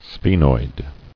[sphe·noid]